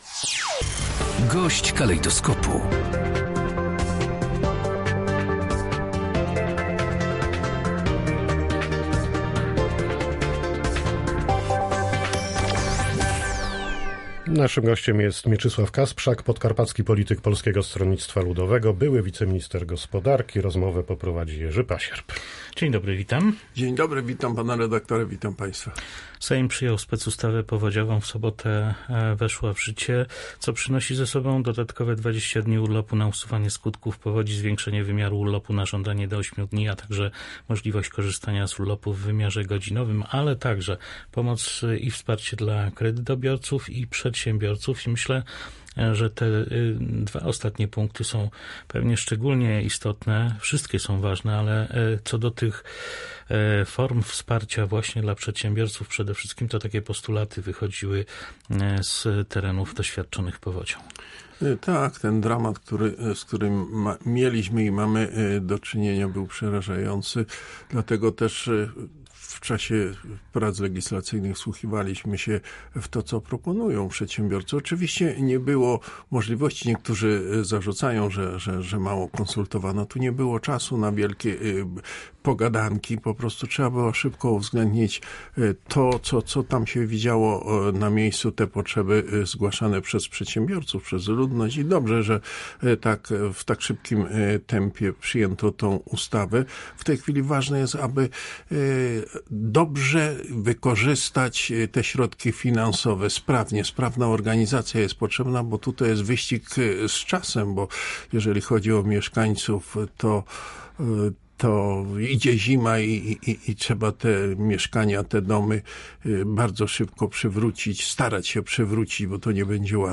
GOŚĆ DNIA. Mieczysław Kasprzak, były poseł PSL
-W tej chwili ważne jest, aby dobrze wykorzystać te środki finansowe – podkreśla gość Polskiego Radia Rzeszów, były poseł PSL Mieczysław Kasprzak.